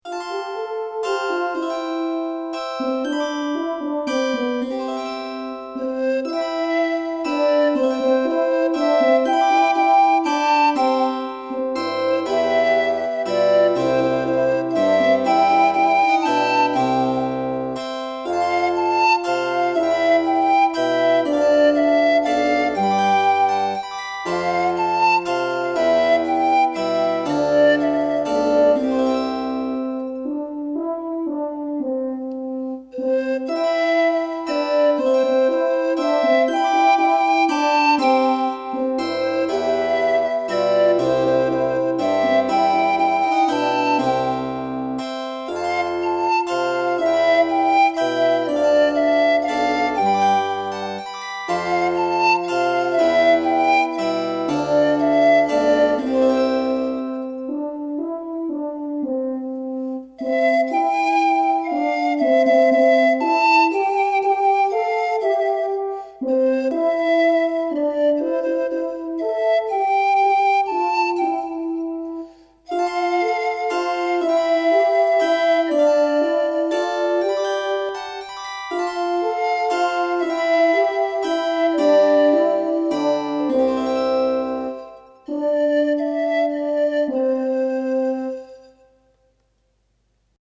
Noël hollandais
Pour flûte de Pan, en do majeur, avec un accompagnement de clavecin, de cor et basson.
(avec flûte)